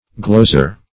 gloser - definition of gloser - synonyms, pronunciation, spelling from Free Dictionary Search Result for " gloser" : The Collaborative International Dictionary of English v.0.48: Gloser \Glos"er\, n. See Glosser .